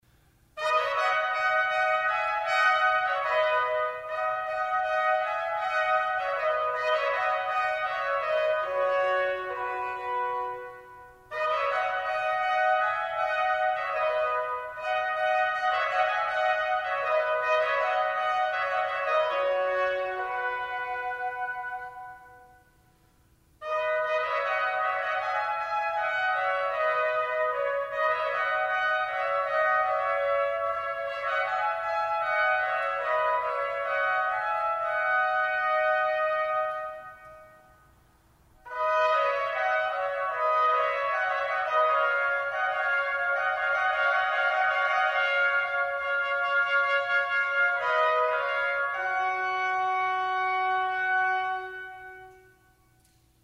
Click here to MP3 audio-file of period trumpet music:
Sonata detta del Castaldi Girolamo Fantini (1600–1675), Sonata detta del Castaldi performed Baroque trumpet ensemble 'Clarino Consort'